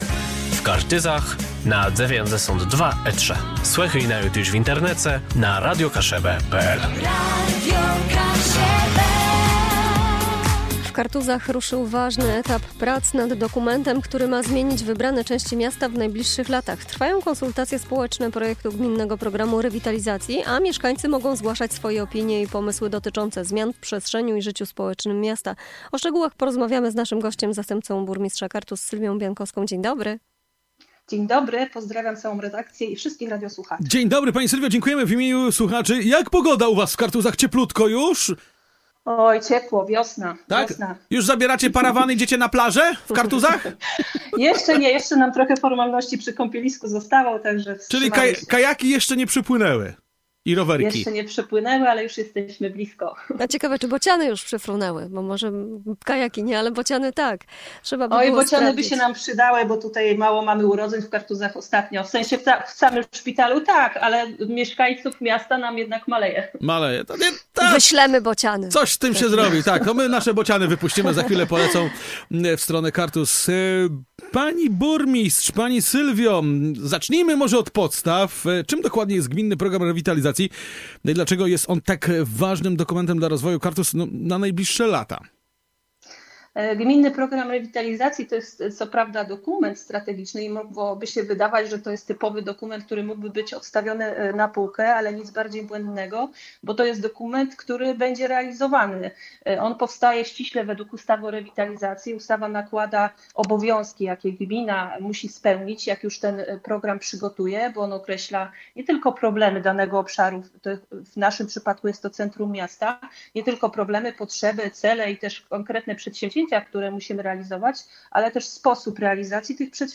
Audycja: Gość Dzień Dobry Kaszuby Rozmawiali